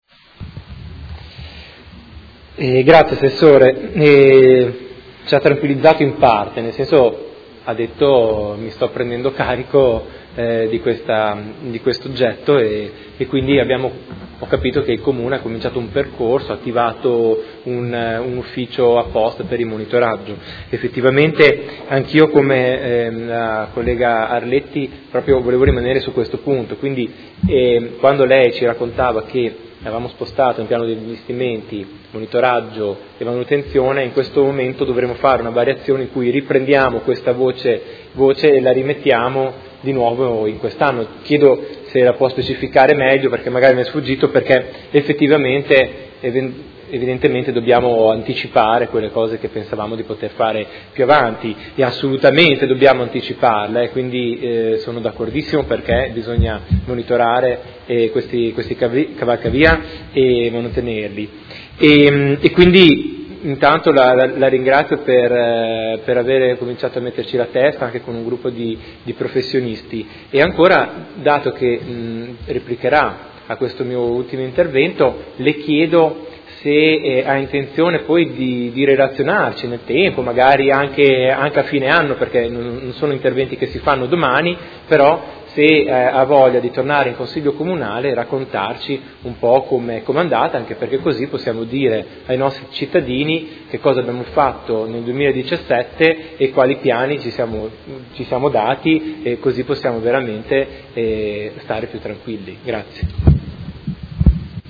Seduta del 18/05/2017. Dibattito su interrogazione dei Consiglieri Chincarini (Per Me Modena) e Arletti (PD) avente per oggetto: Qual è lo stato di sicurezza dei cavalcavia di competenza del Comune di Modena?